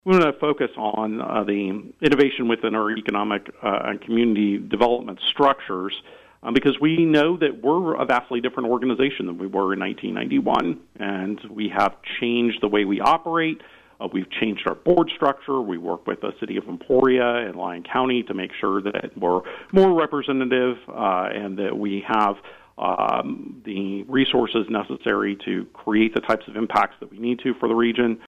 an interview on KVOE’s Talk of Emporia Monday morning